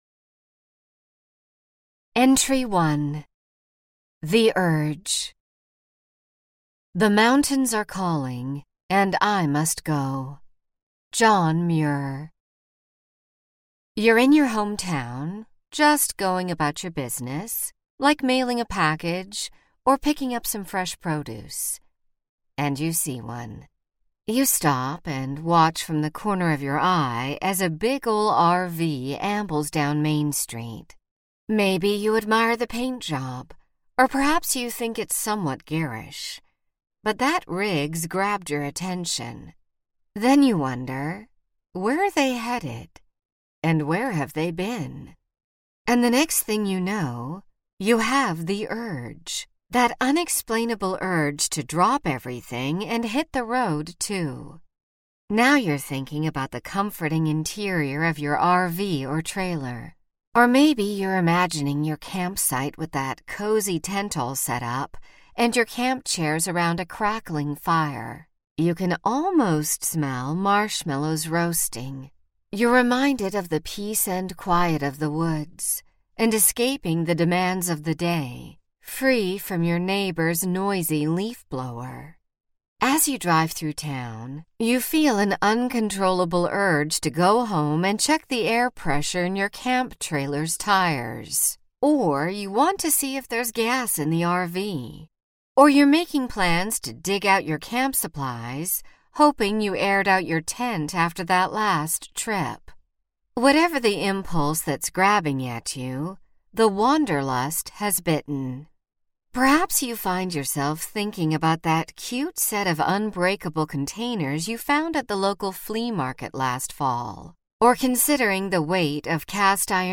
Gathered Around the Campfire Audiobook
Narrator
4.8 Hrs. – Unabridged